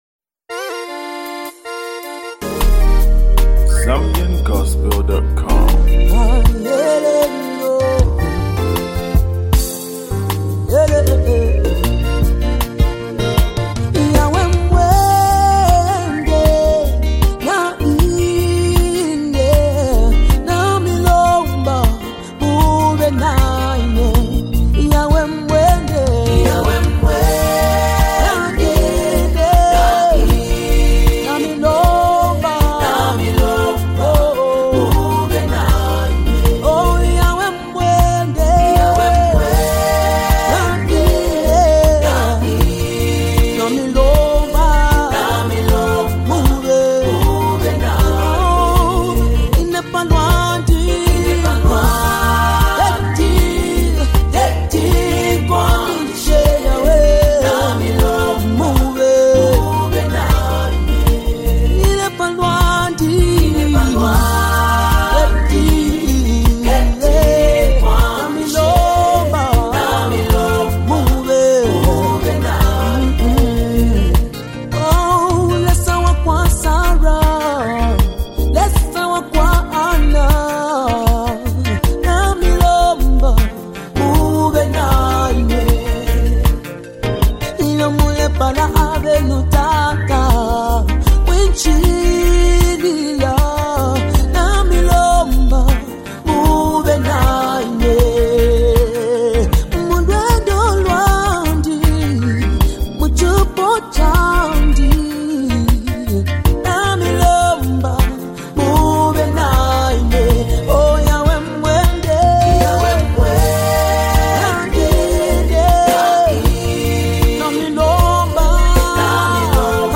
Gospel Music
captivating and spirit-filled worship anthem
emotive delivery and soulful voice